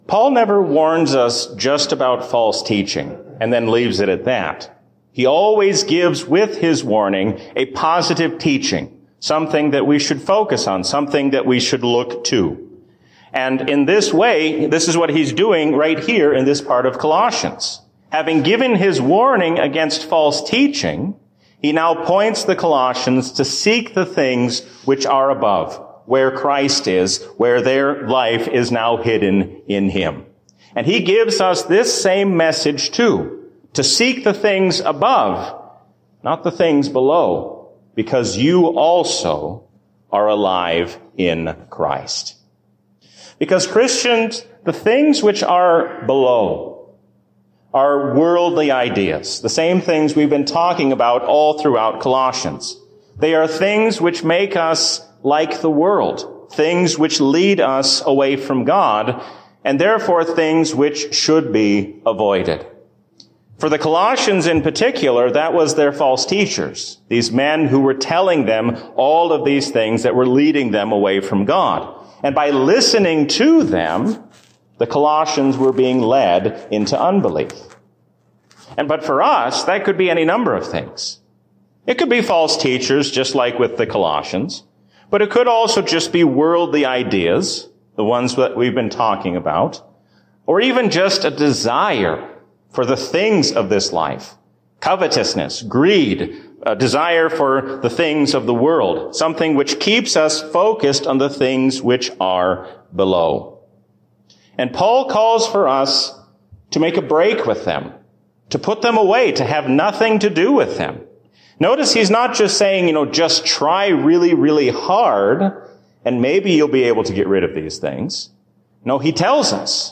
A sermon from the season "Trinity 2024." The face of Moses shines to show us that we should listen to God.